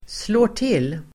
Uttal: [slå:r_t'il:]